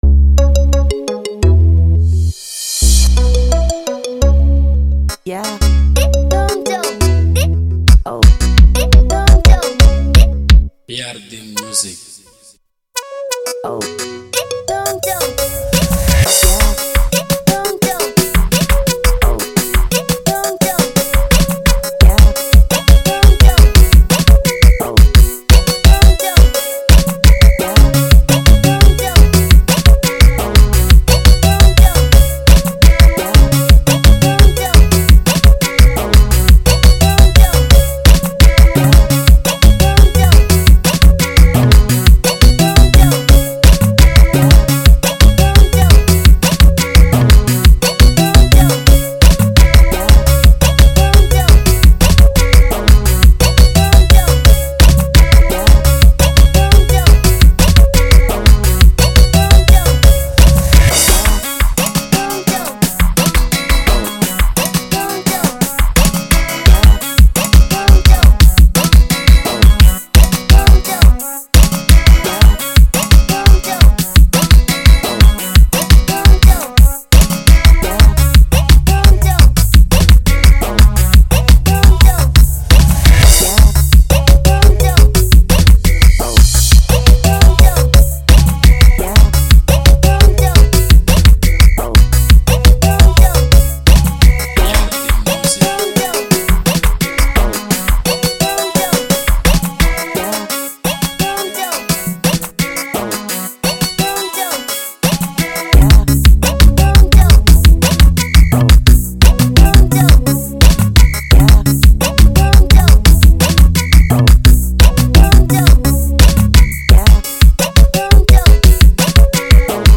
05:16 Genre : Xitsonga Size